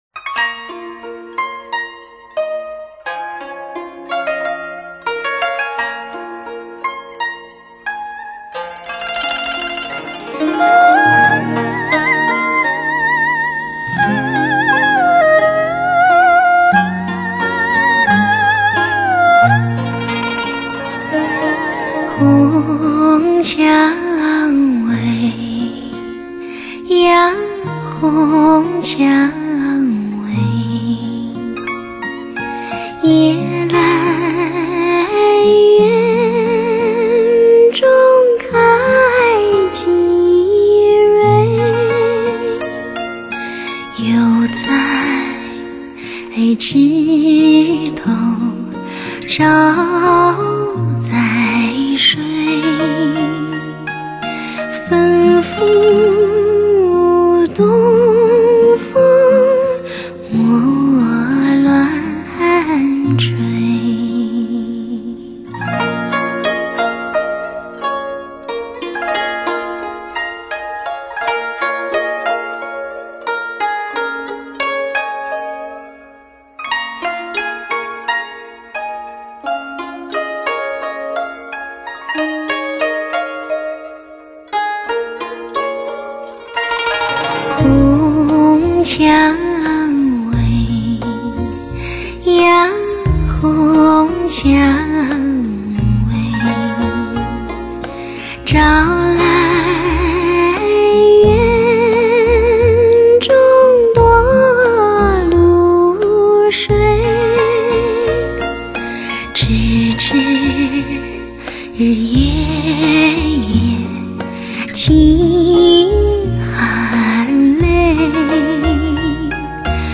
甜美 细腻的嗓音 不得不让人喜欢听